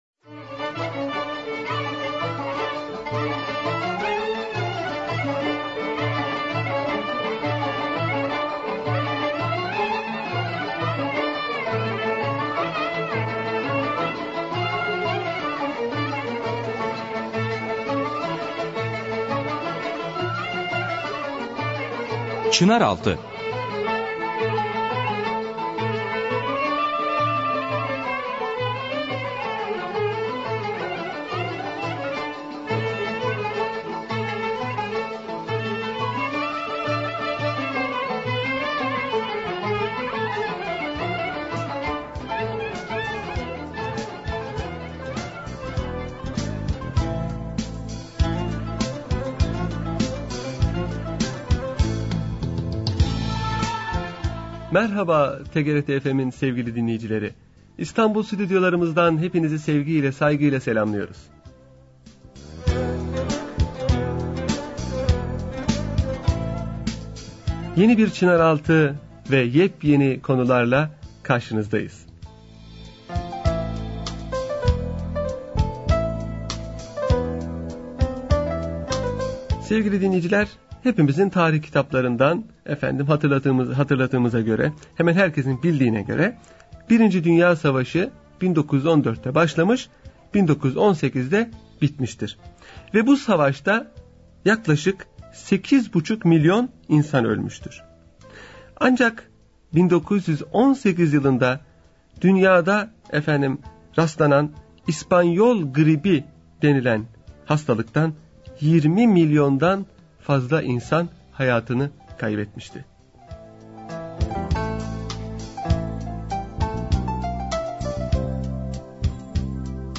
Radyo Programi - Çeşitli Anektotlar